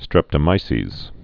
(strĕptə-mīsēz)